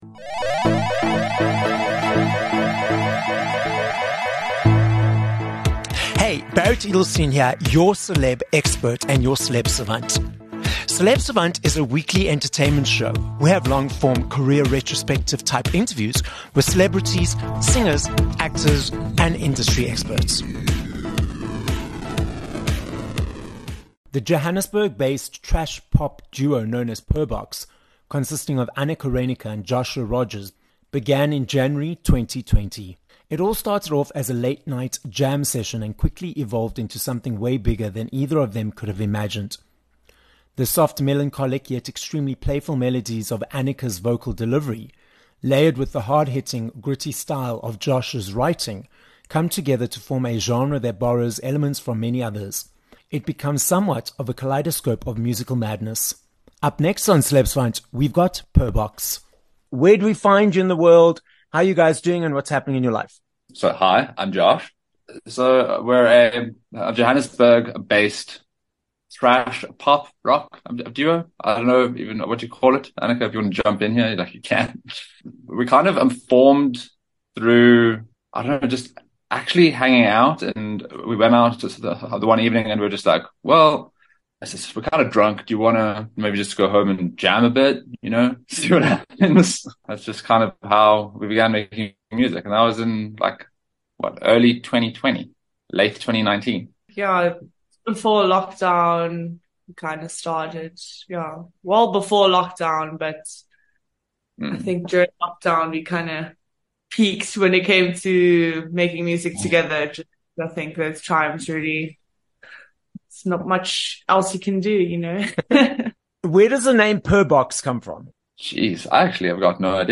4 Feb Interview with Purrbox